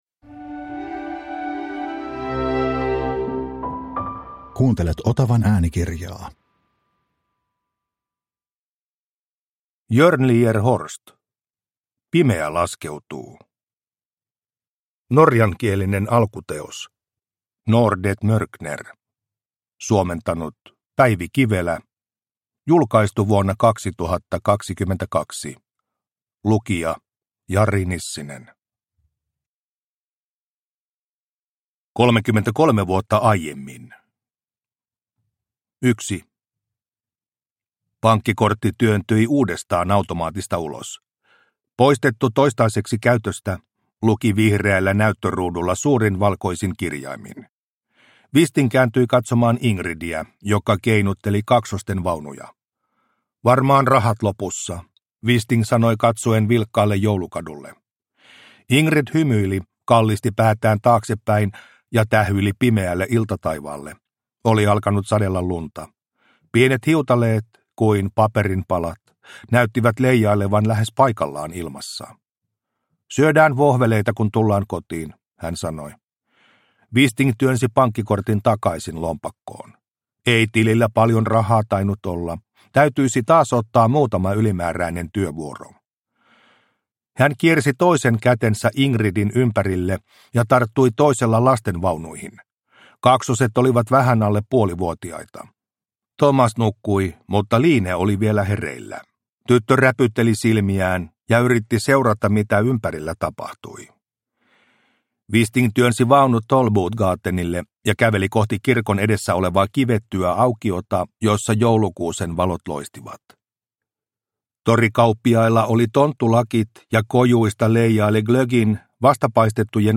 Pimeä laskeutuu – Ljudbok – Laddas ner